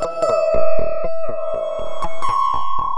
The stalker (Lead) 120BPM.wav